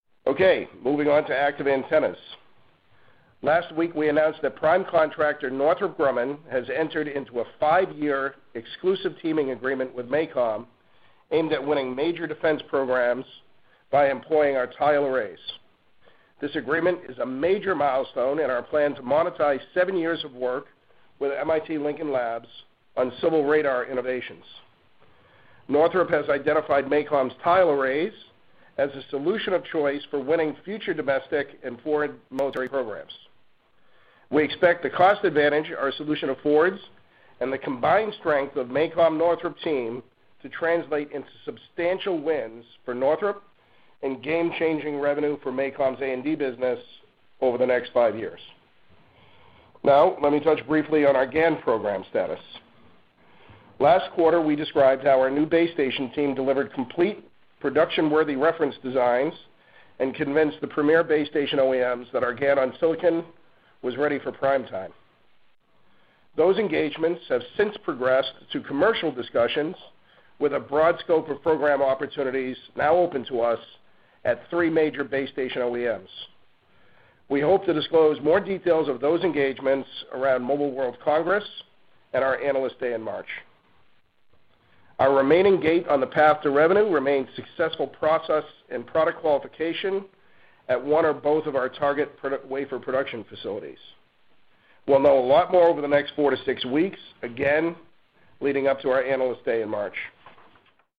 MACOM Earnings Call Color